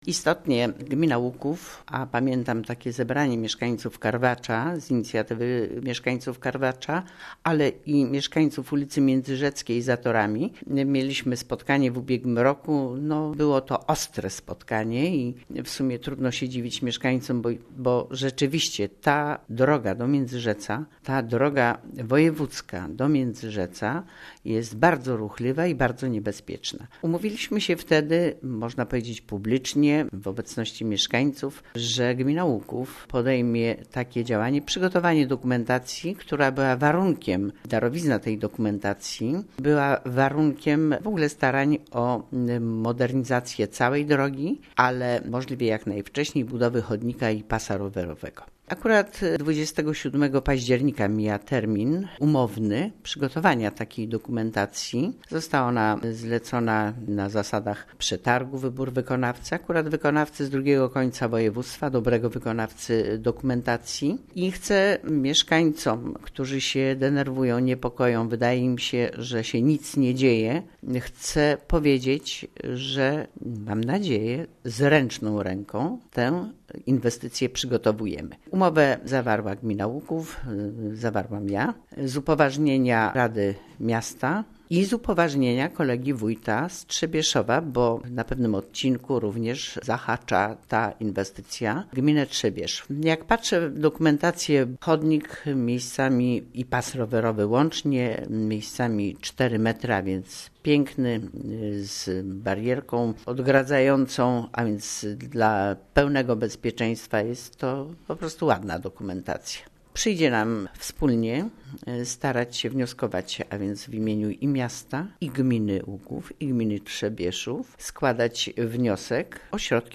W Łukowie rozpoczęła sie modernizacja ul. Międzyrzeckiej. Oprócz związanych z tym problemów komunikacyjnych wśród mieszkańców tej ulicy szczególnie na odcinku za torami i miejscowości Karwacz pojawiła sie nadzieja na szybką budowę zapowiadanego już od dawna chodnika. O szczegółach informuje:
Wójt Gminy Łuków